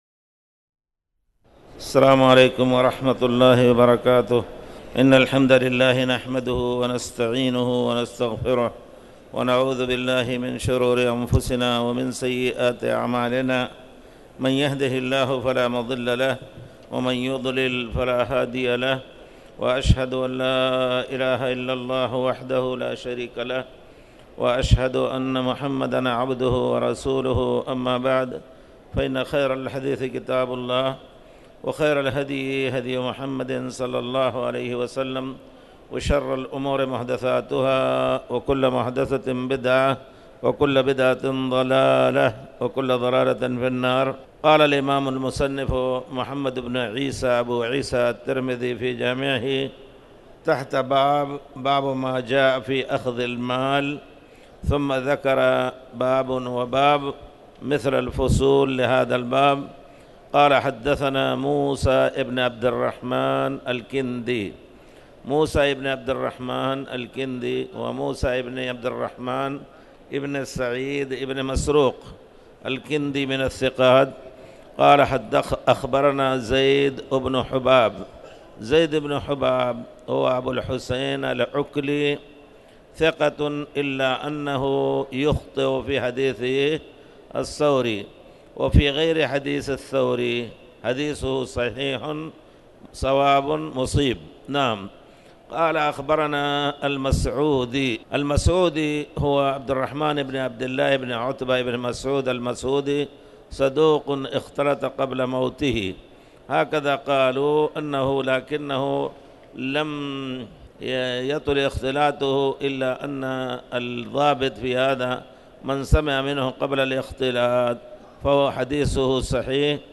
تاريخ النشر ١ جمادى الأولى ١٤٣٩ هـ المكان: المسجد الحرام الشيخ